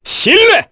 Clause-Vox_awk_03_kr.wav